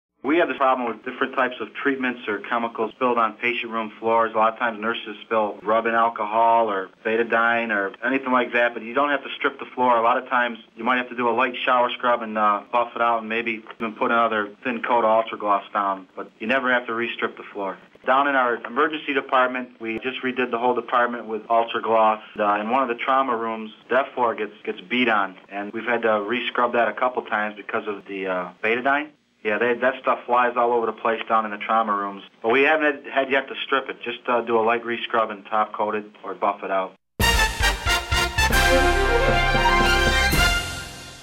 Hospital ES Manager About Ultra Gloss Standing Up to Betadine®   37 Seconds